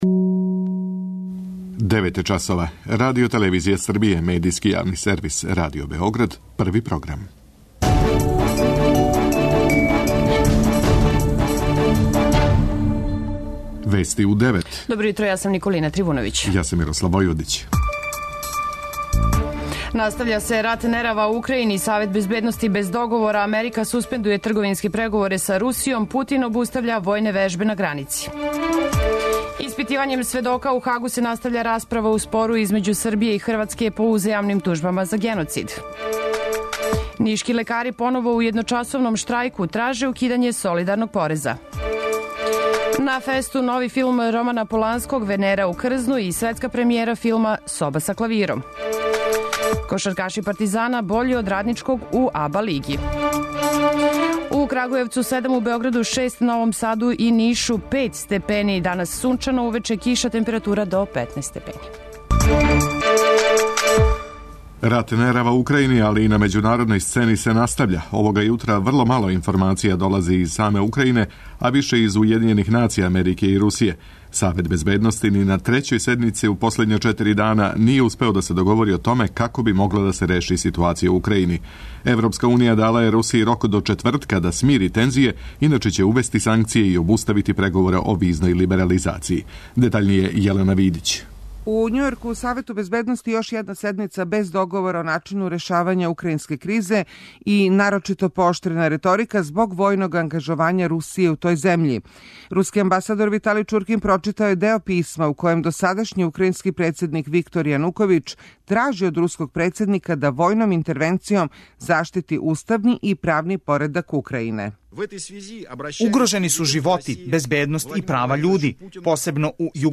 преузми : 10.39 MB Вести у 9 Autor: разни аутори Преглед најважнијиx информација из земље из света.